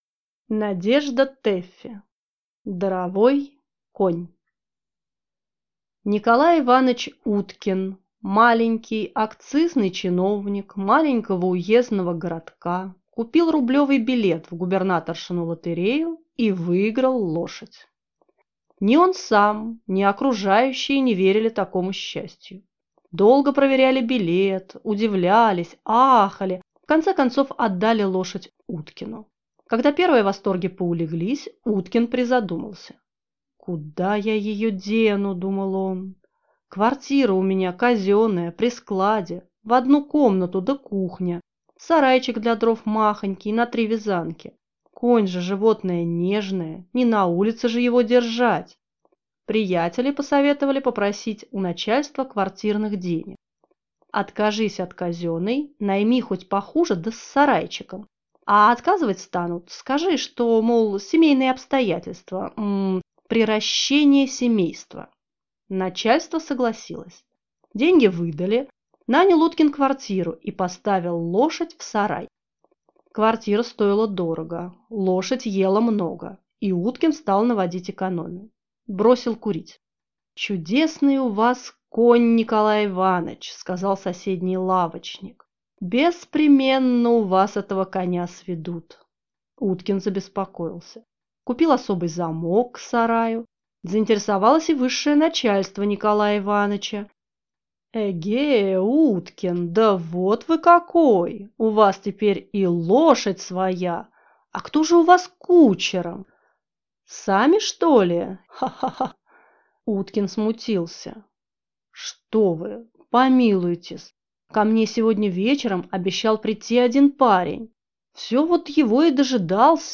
Аудиокнига Даровой конь | Библиотека аудиокниг